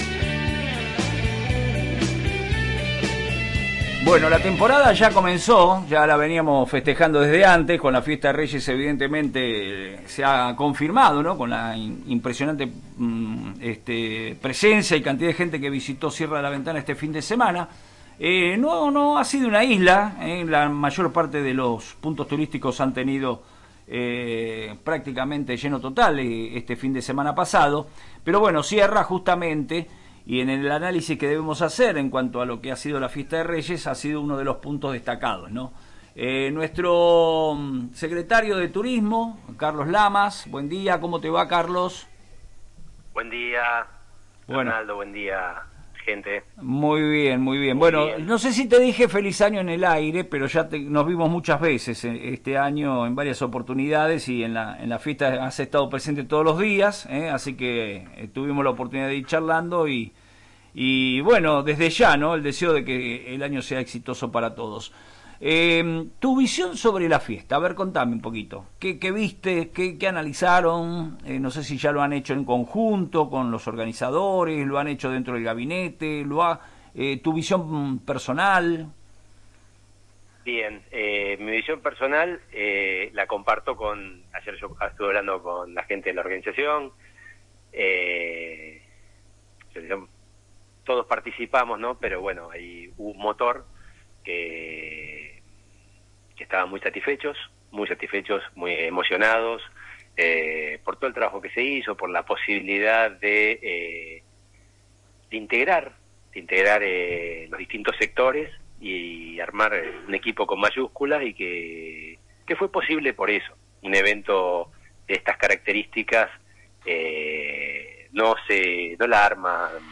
En otro tramo de la entrevista, el Secretario de Turismo se refirió al movimiento en las aproximadamente 8.000 plazas que ofrece el distrito para este verano.